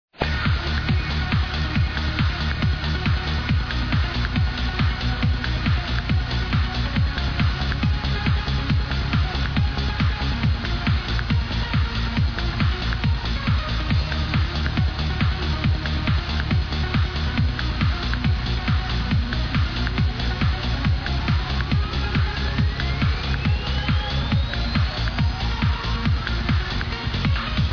This tune was played in Kolobrzeg at sunrise party
Please ID this trance trax